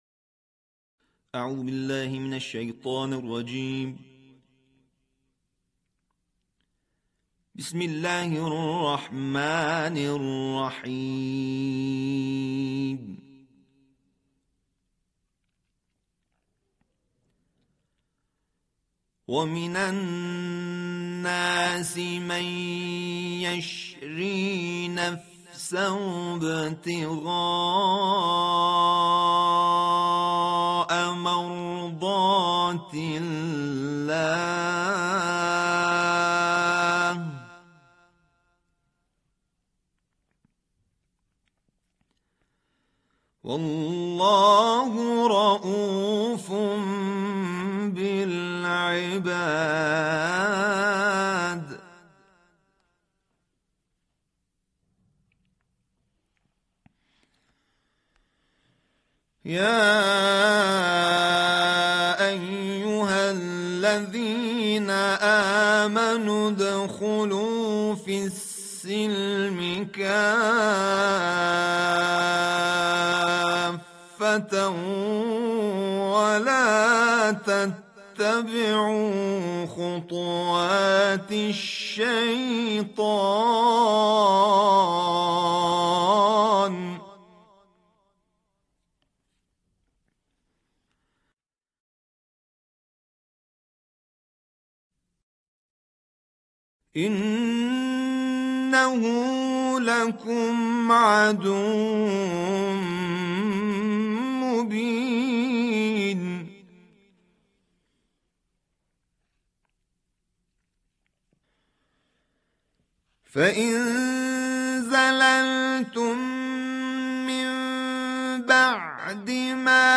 تلاوت تحقیق